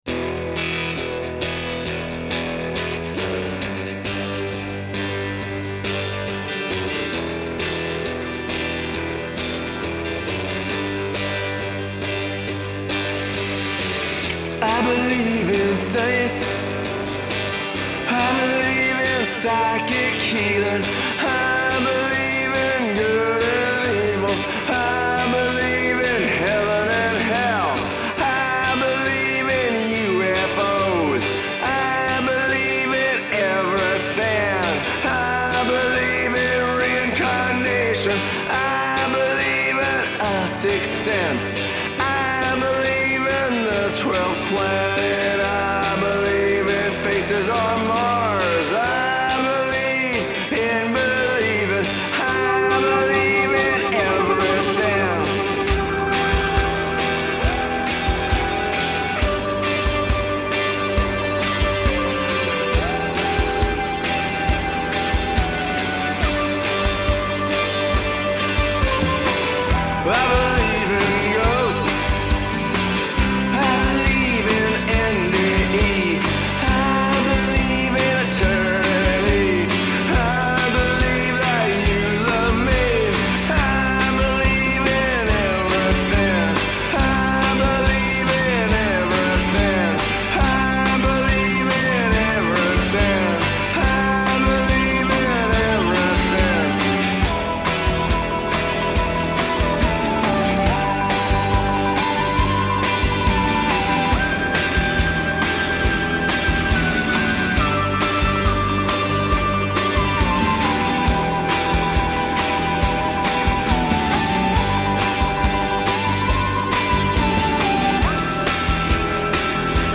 Pure punchin' the air rock'n'roll thrills.